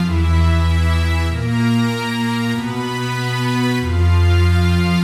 Index of /musicradar/80s-heat-samples/95bpm
AM_80sOrch_95-E.wav